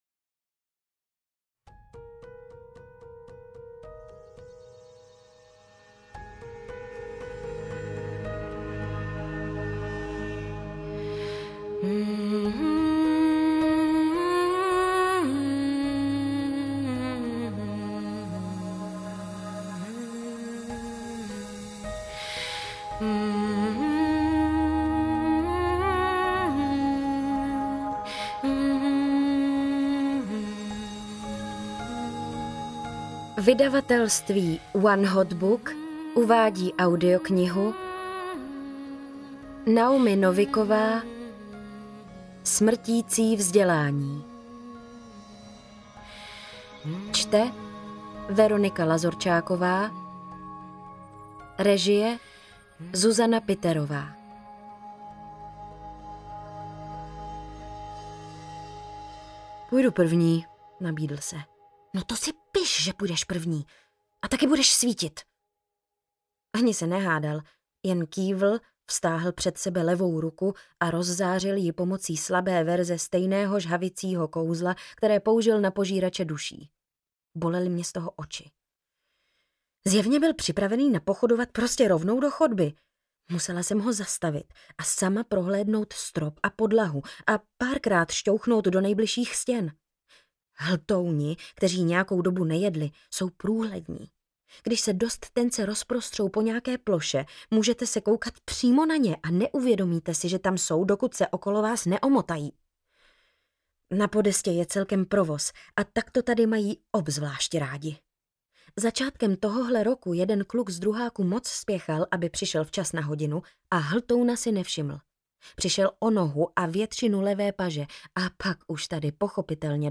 Smrtící vzdělání audiokniha
Ukázka z knihy
smrtici-vzdelani-audiokniha